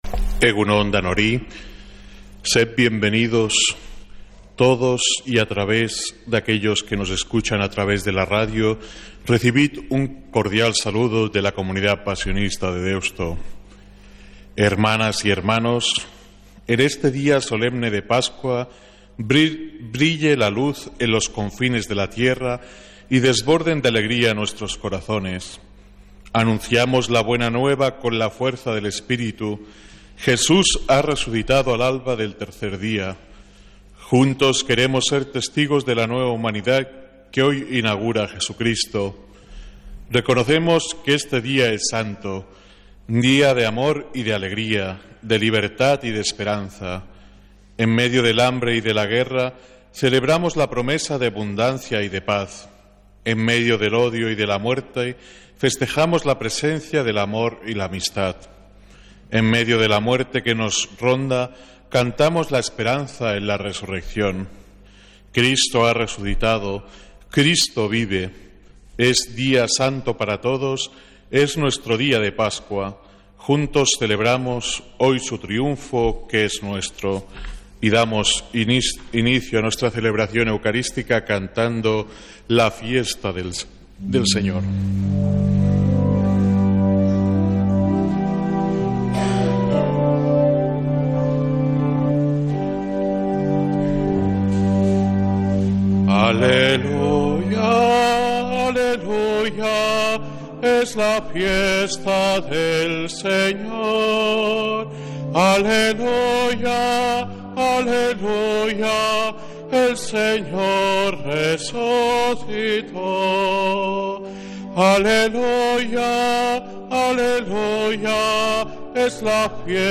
Santa Misa desde San Felicísimo en Deusto, domingo 20 de abril